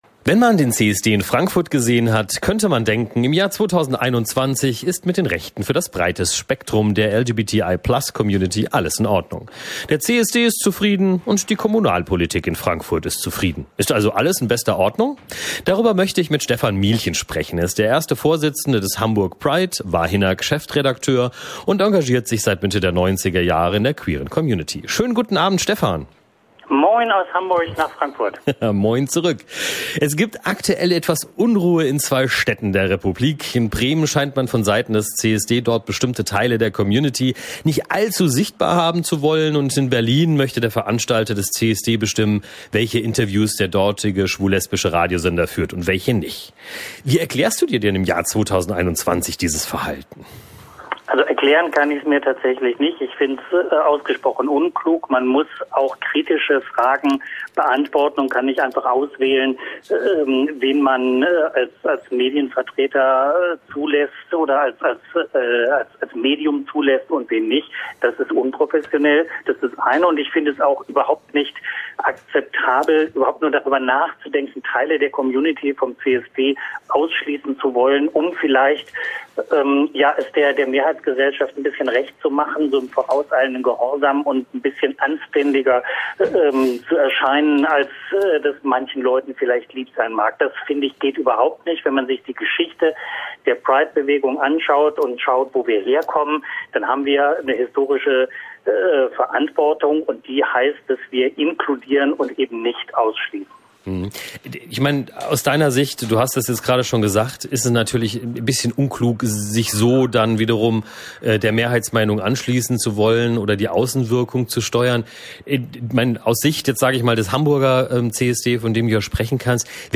im Gespräch mit